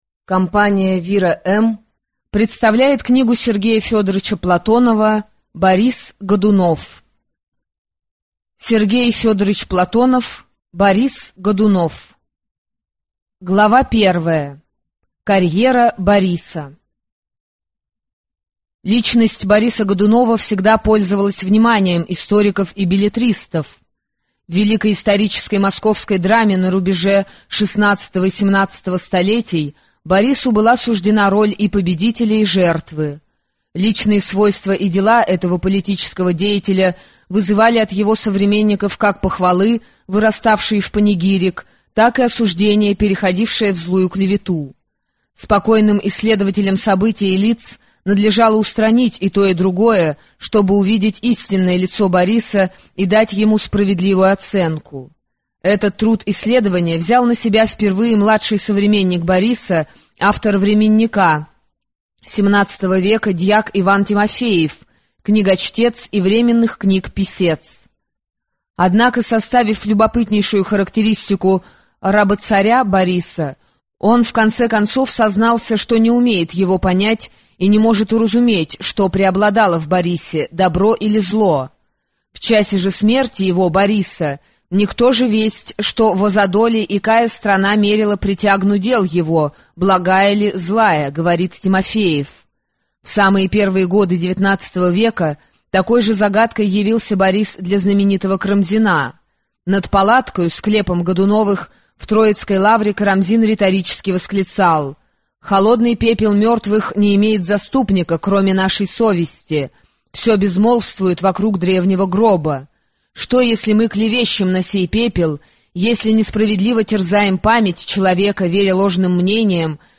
Аудиокнига Борис Годунов | Библиотека аудиокниг